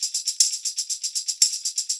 120 TAMB2.wav